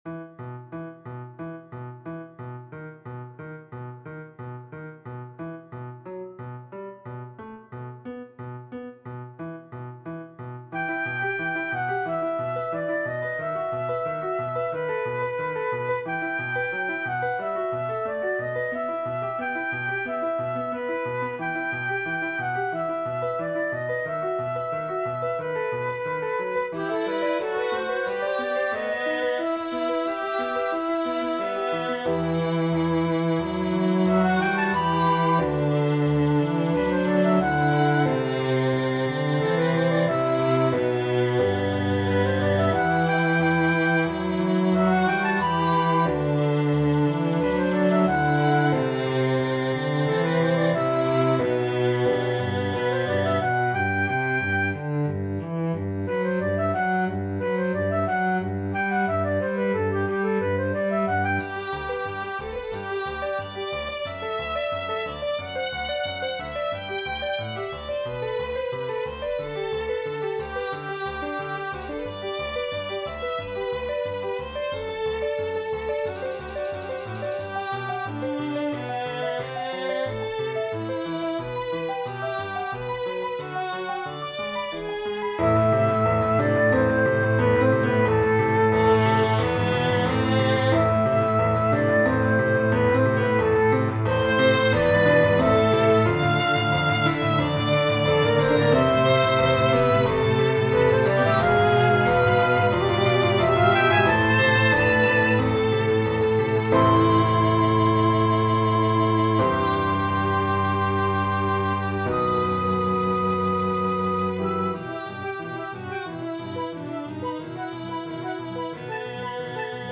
This piece I finished composing yesterday represents rain falling and the sadness of a person wishing that it stopped raining. The legato represents the sadness and the staccato and tremolos represent the rain itself. There are quite a few modulations that occur in my piece.